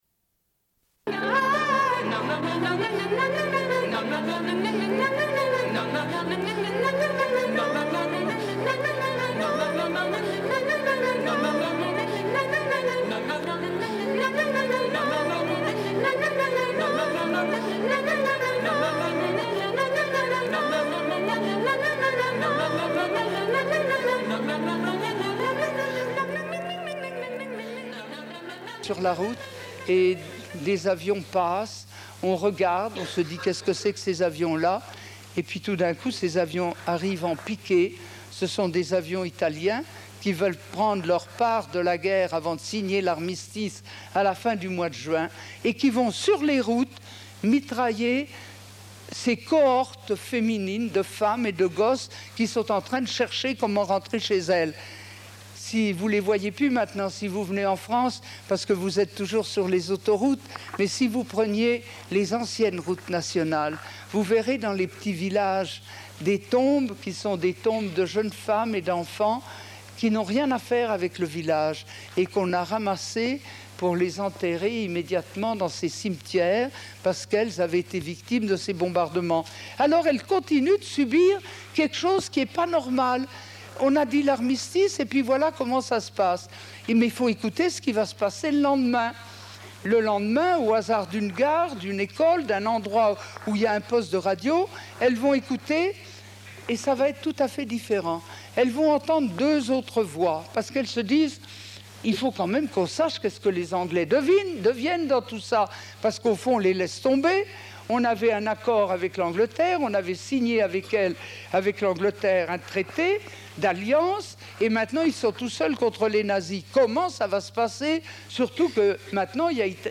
Suite de l'émission : diffusion d'une rencontre avec Lucie Aubrac à l'occasion d'une conférence au Collège de Staël sur les femmes et la résistance en France.